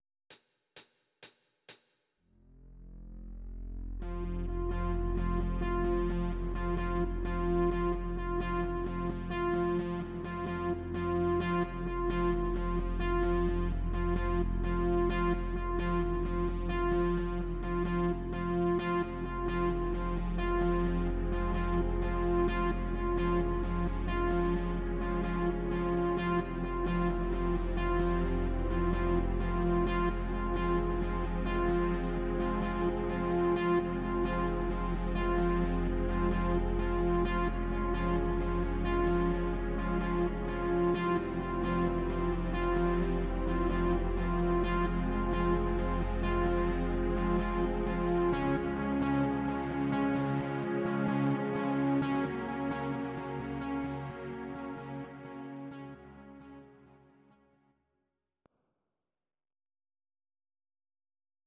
These are MP3 versions of our MIDI file catalogue.
Please note: no vocals and no karaoke included.
Your-Mix: Instrumental (2065)